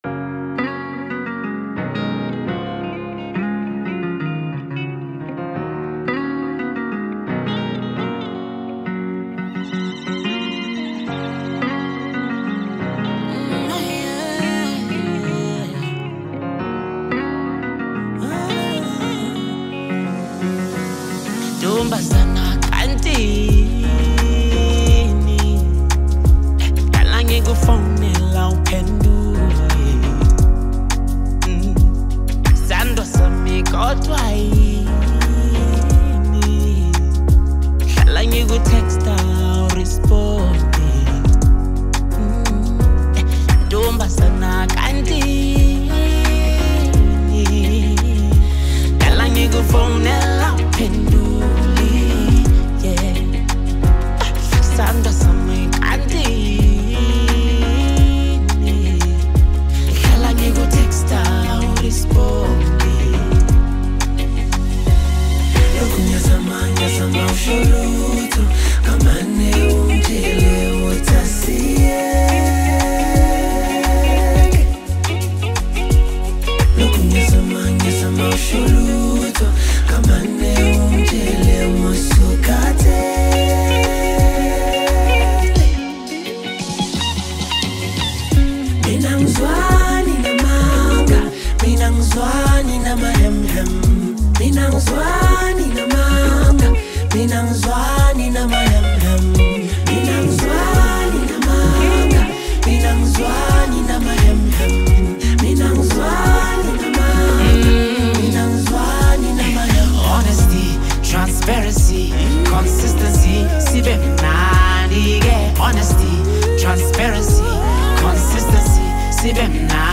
Foreign MusicSouth African
soulful vocals
With its infectious rhythm and powerful lyrics